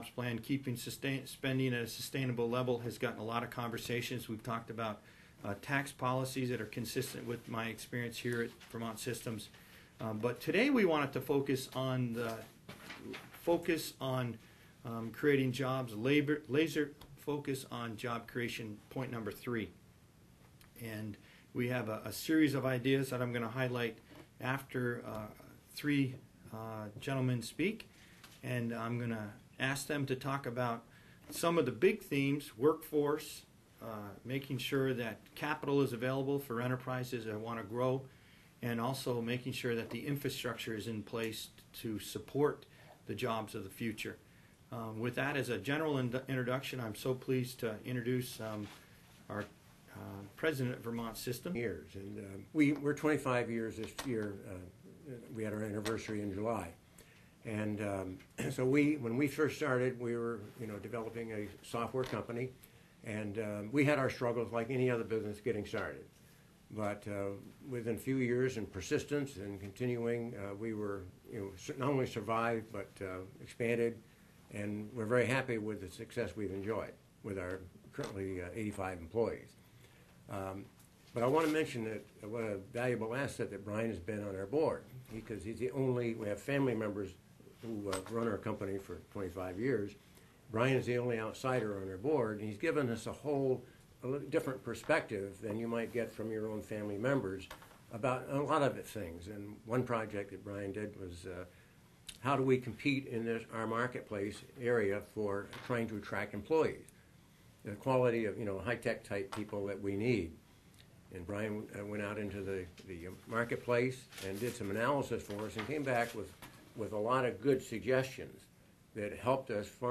Lt. Gov. Brian Dubie, press conference, Oct. 25, 2010 Courtesy of FOX44.